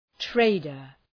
{‘treıdər}